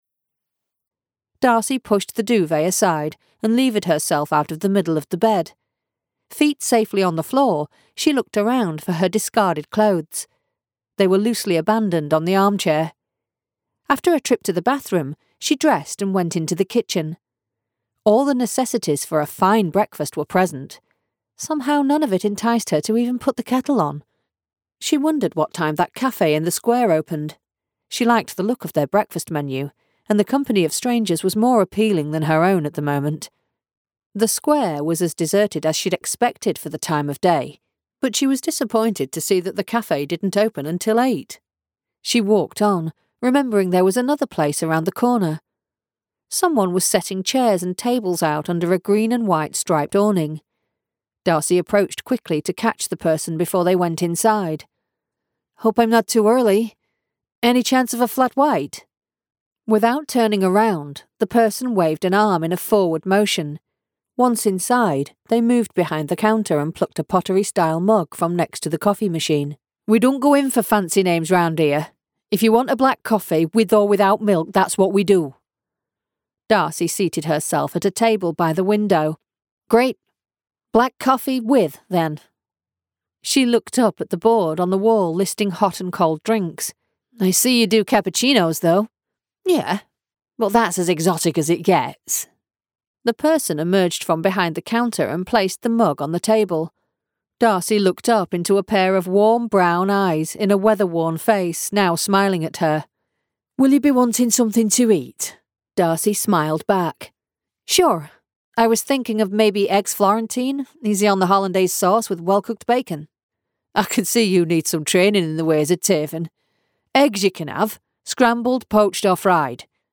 Unabridged Audiobook